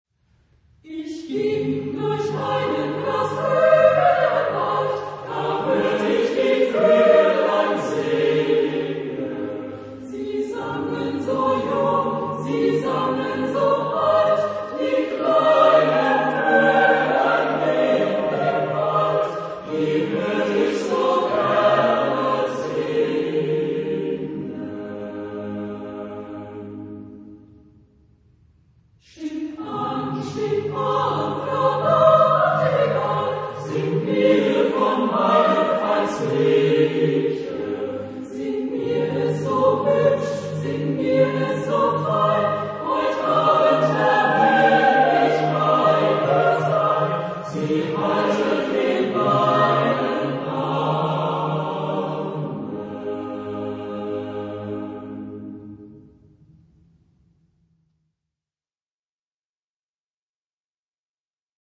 Genre-Style-Form: Folk music ; Partsong ; Secular
Type of Choir: SSAATB  (6 mixed voices )
Tonality: A flat major